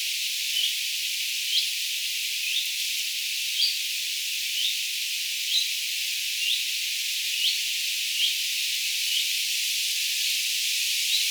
hyit-tiltaltti.mp3